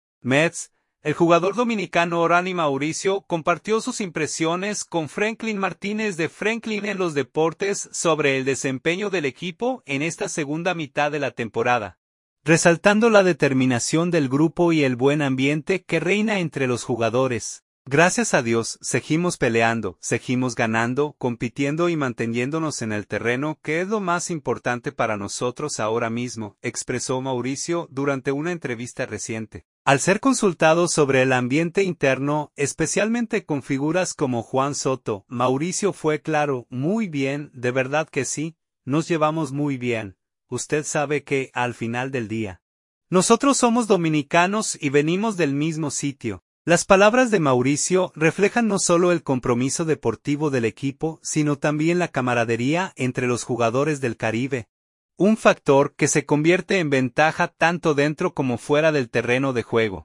“Gracias a Dios, seguimos peleando, seguimos ganando, compitiendo y manteniéndonos en el terreno, que es lo más importante para nosotros ahora mismo”, expresó Mauricio durante una entrevista reciente.